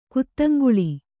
♪ kuttaŋgaḷi